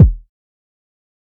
TC3Kick17.wav